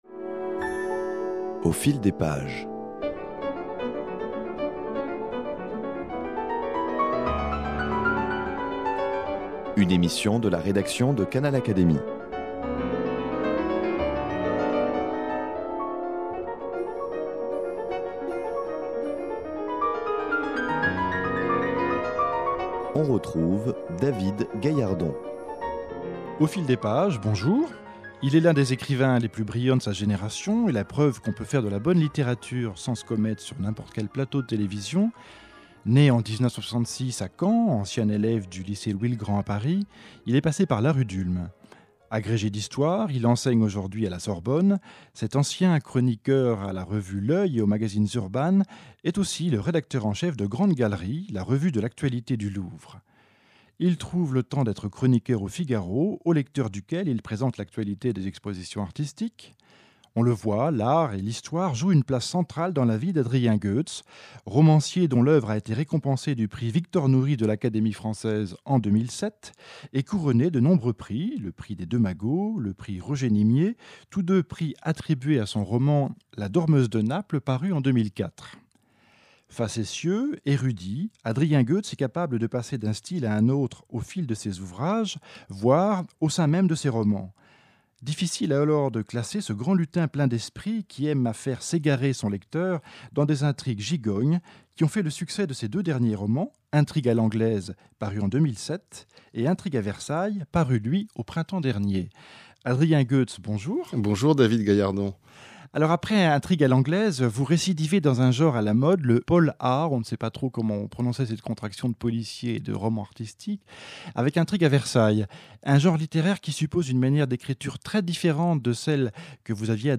Entretien avec Adrien Goetz, lauréat du prix Victor-Noury de l’Académie française